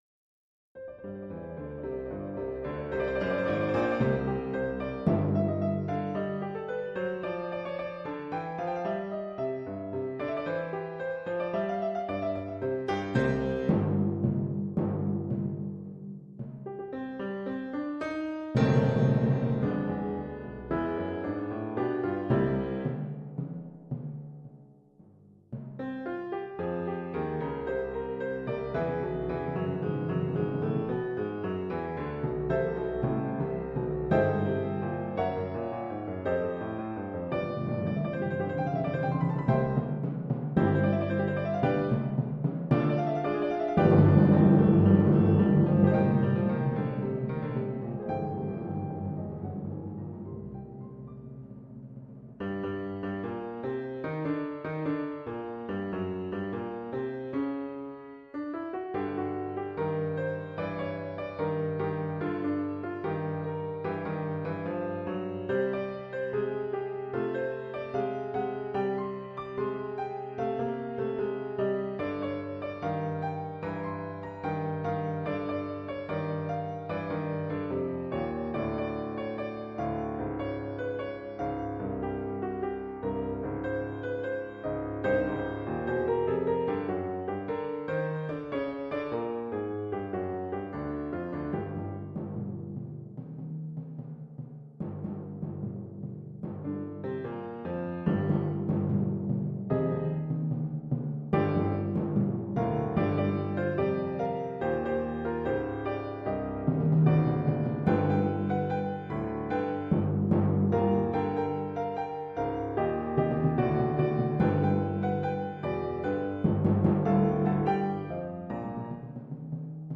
Percussions
Oeuvre pour quatre timbales et piano.